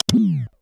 switch-off.mp3